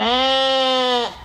sheep1.wav